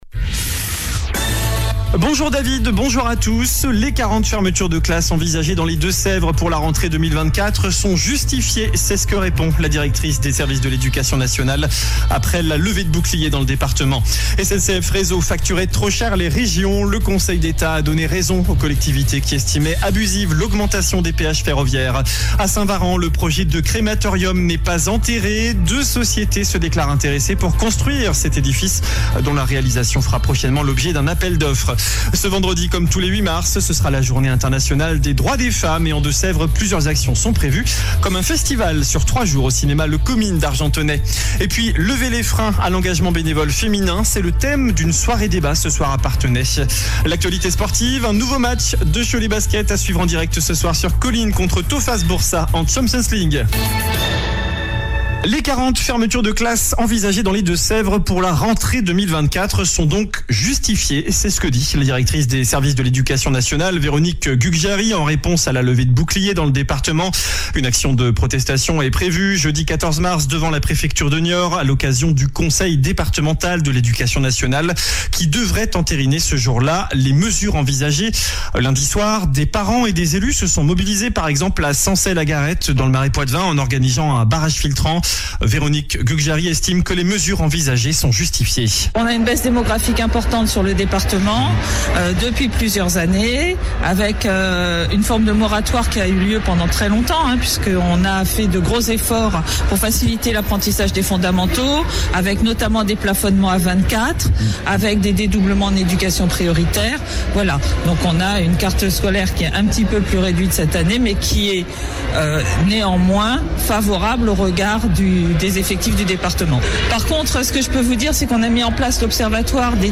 JOURNAL DU MERCREDI 06 MARS ( MIDI )